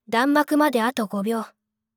弾幕5.wav